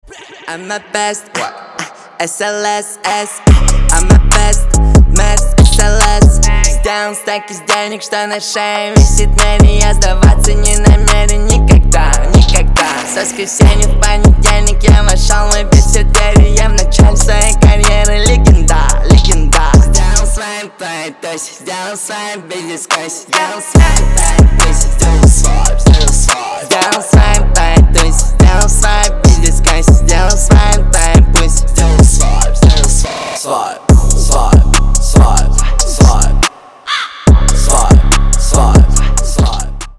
Рэп и Хип Хоп
громкие # клубные